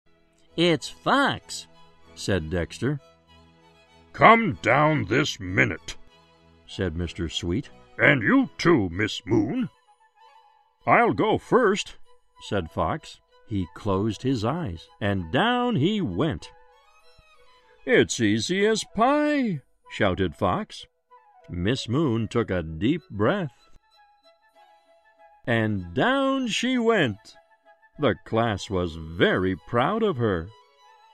在线英语听力室小狐外传 第37期:德克斯特的听力文件下载,《小狐外传》是双语有声读物下面的子栏目，非常适合英语学习爱好者进行细心品读。故事内容讲述了一个小男生在学校、家庭里的各种角色转换以及生活中的趣事。